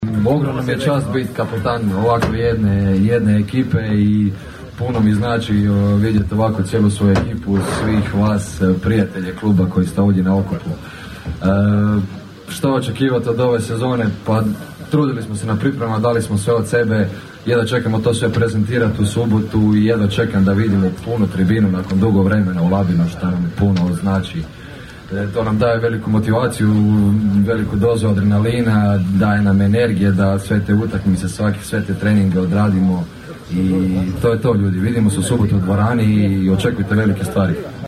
Pod nazivom "Season Kick-off 2025./26.", Rukometni klub Rudar jučer je u prelijepom ambijentu Glamping campa Floria predstavio viziju, ciljeve, momčad, stručni stožer i novi Izvršni odbor za nadolazeću sezonu.